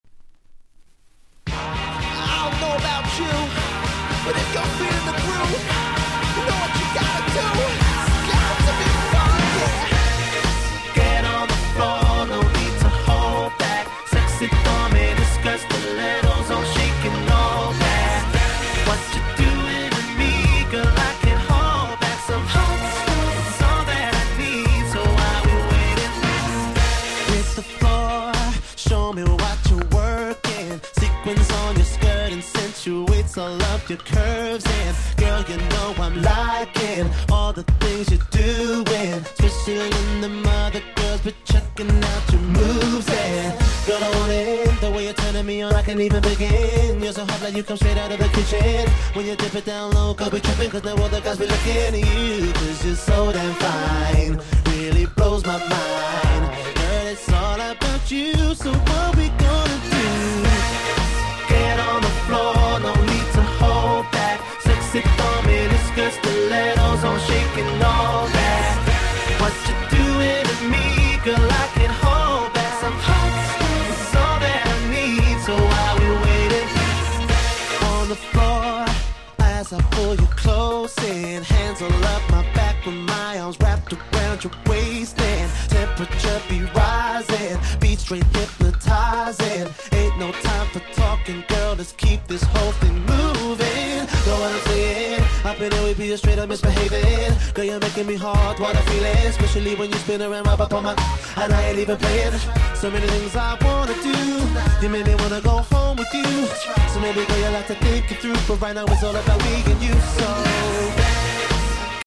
08' Smash Hit R&B !!